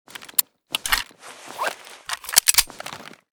cz75_reload_empty.ogg.bak